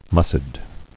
[mus·cid]